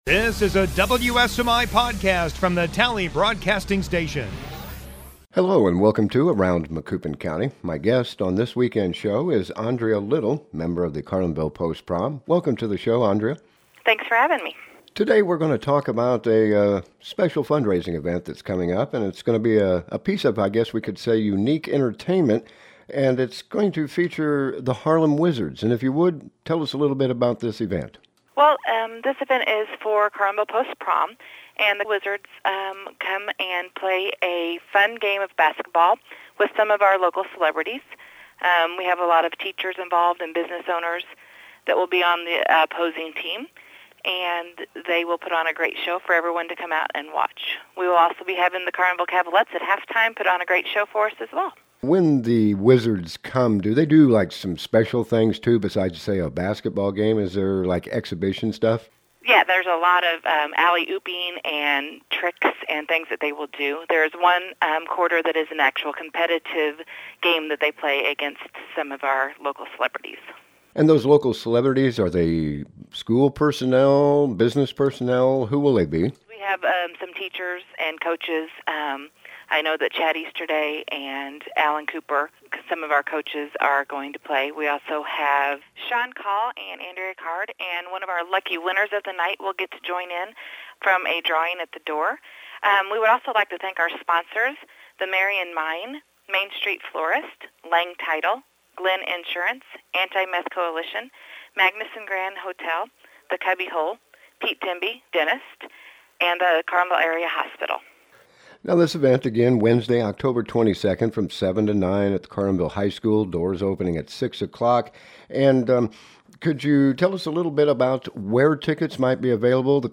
Guest: